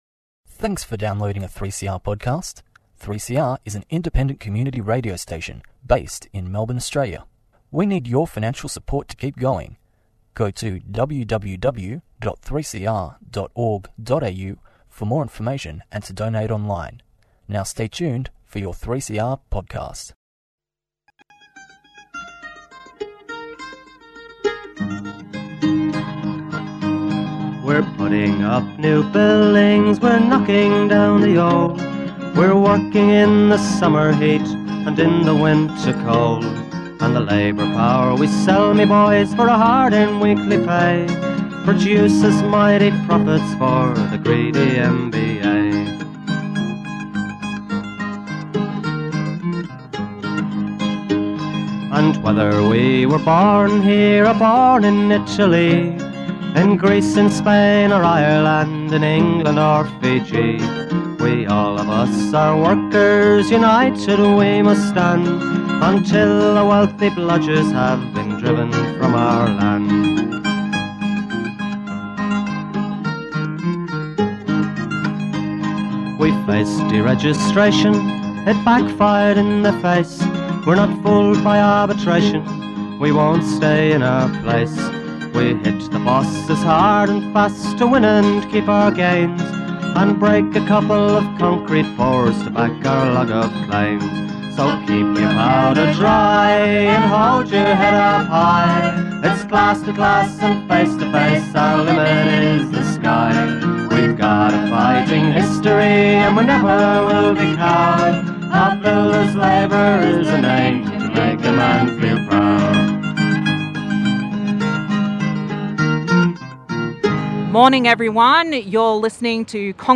Weekly update from the CFMEU Construction & General (Vic/Tas). To celebrate International Working Women's Day the Gang is all women members today.